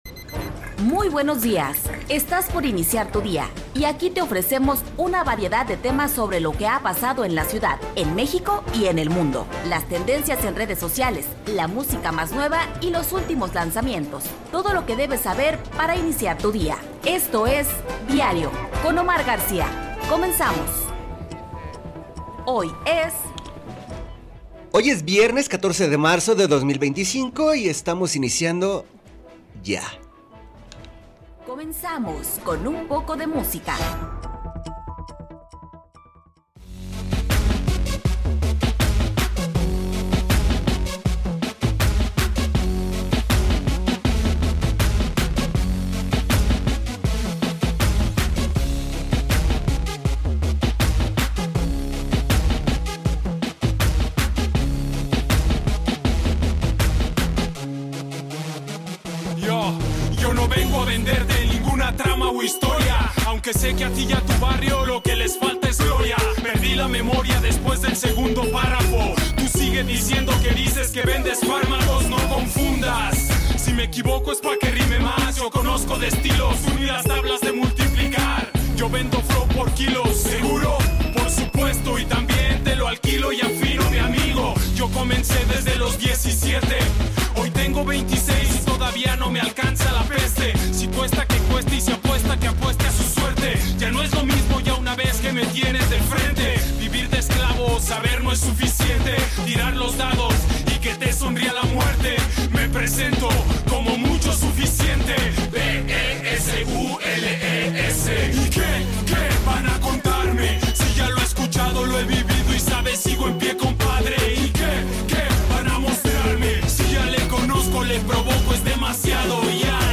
Revista Informativa de Radio Universidad de Guadalajara